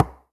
Knock18.wav